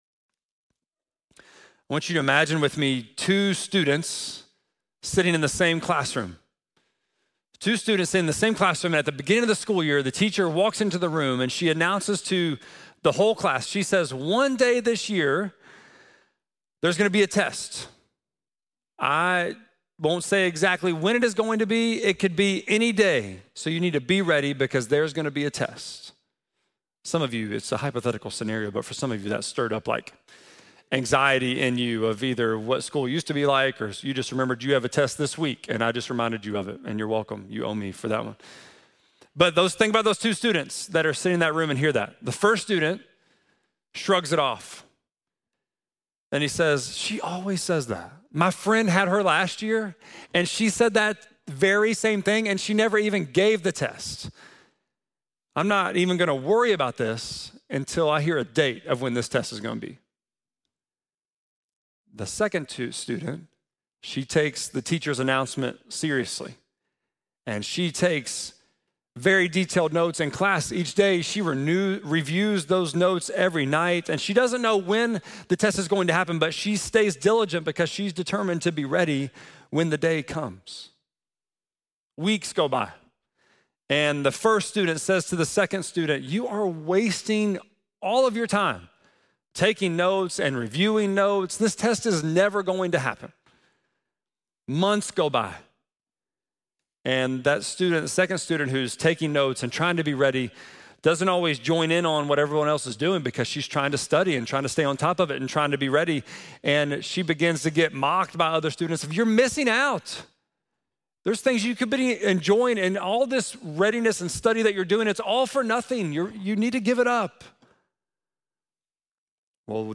11.9-sermon.mp3